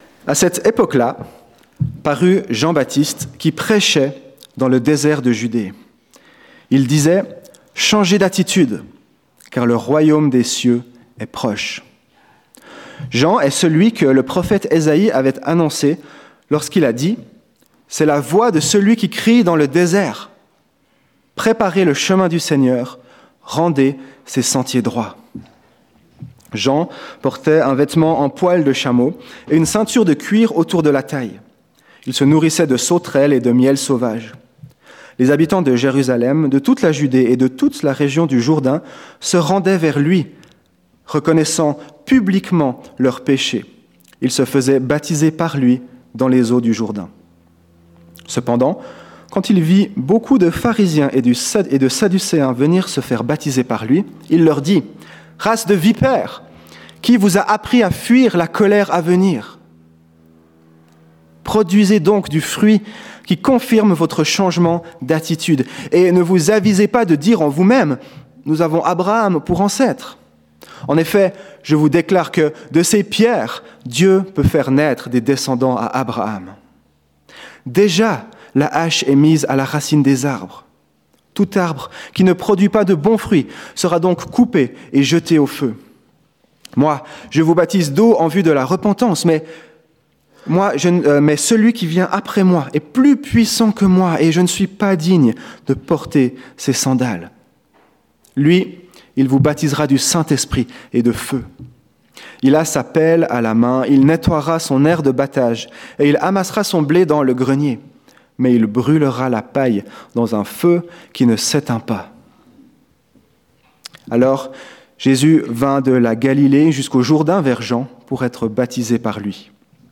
Prédications Vous trouverez ici une sélection de prédications données récemment lors de nos cultes.